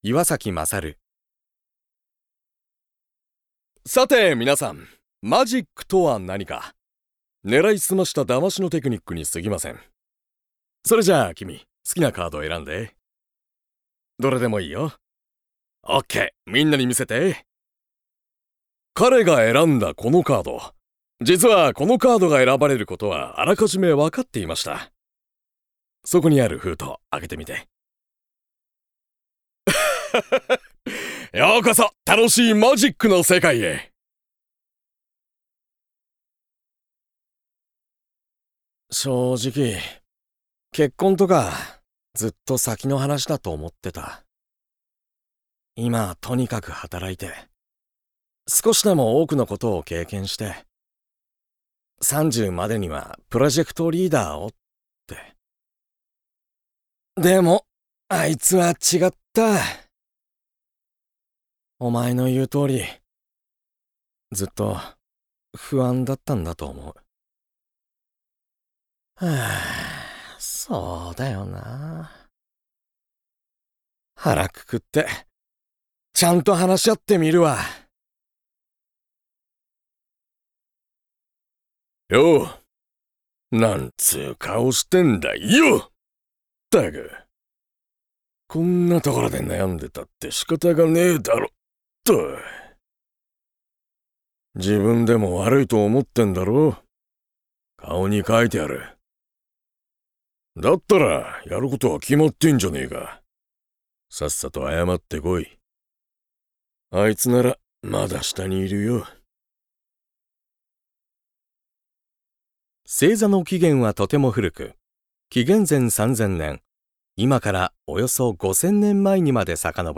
VOICE SAMPLE